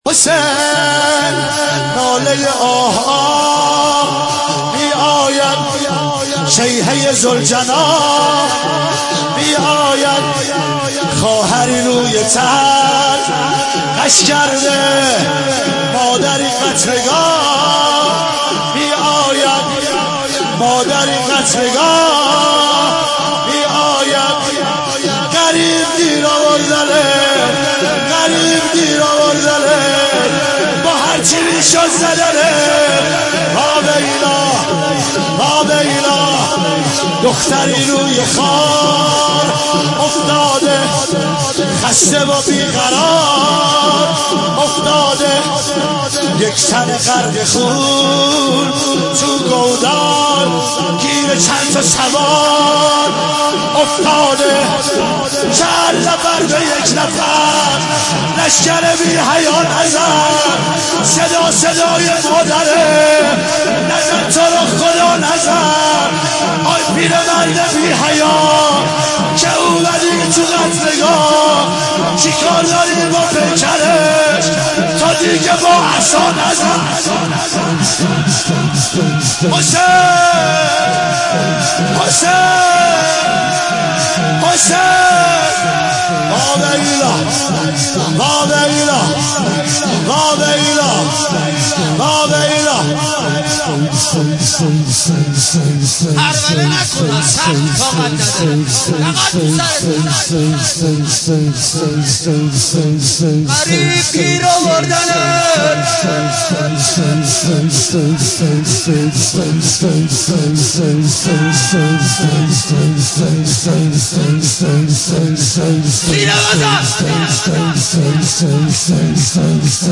مداحی اربعین
شور